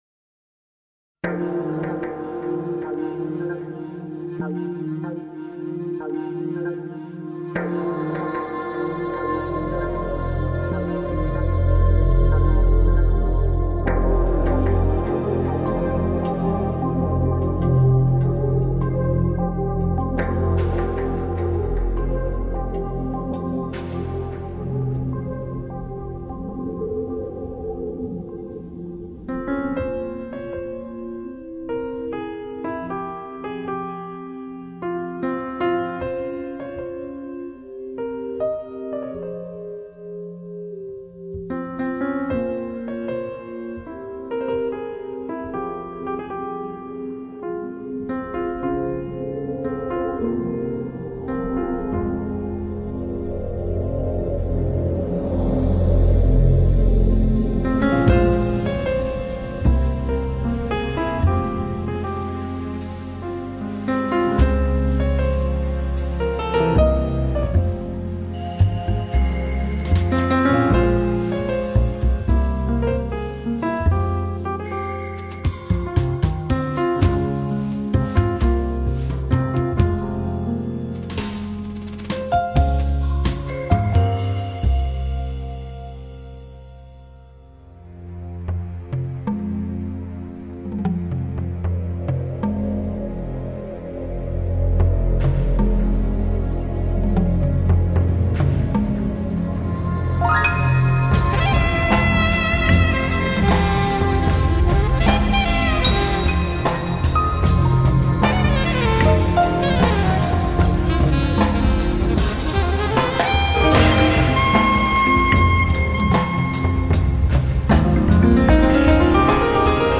Файл в обменнике2 Myзыкa->Джаз